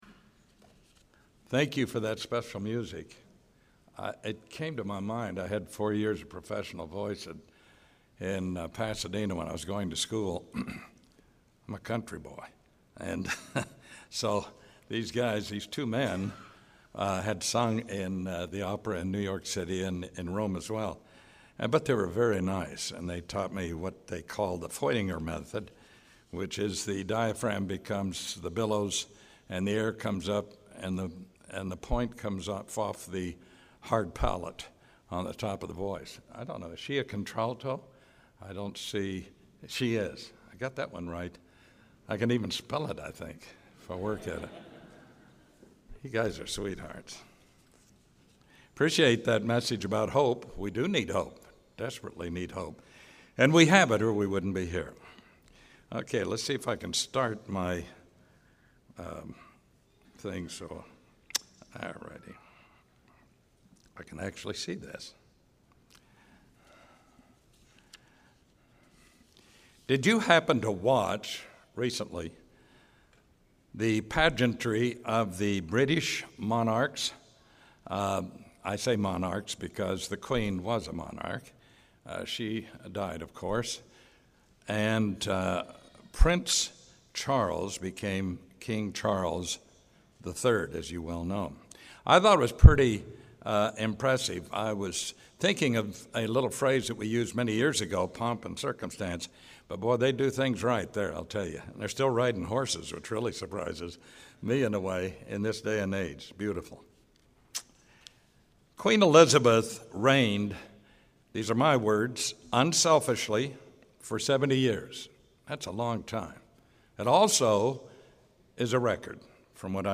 This sermon was given at the Jekyll Island, Georgia 2022 Feast site.